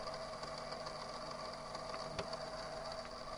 tape_loop.wav